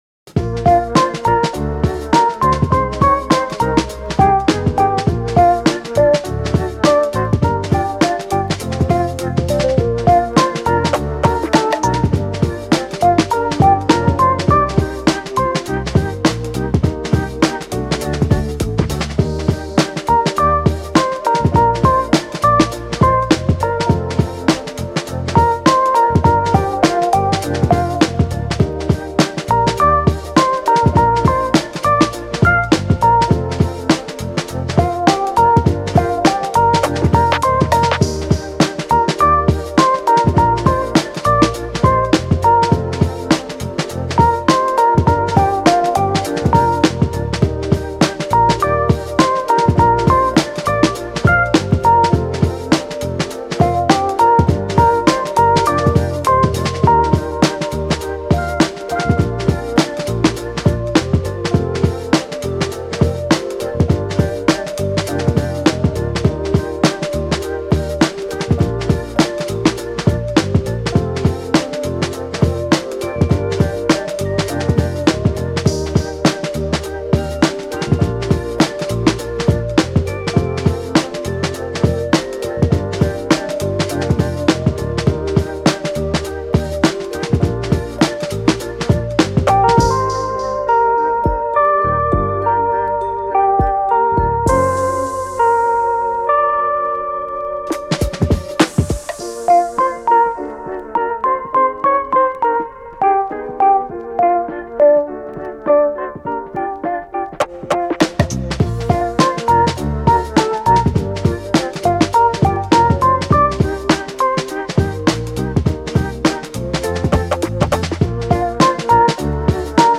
チル・穏やか